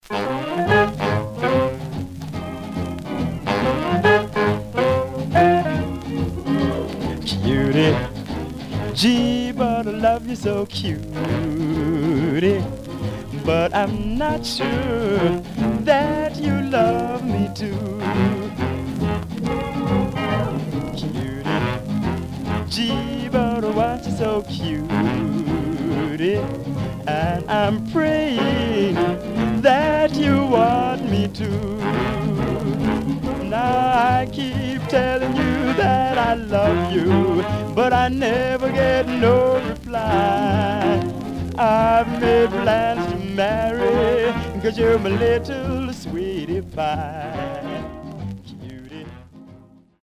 Surface noise/wear Stereo/mono Mono
Rythm and Blues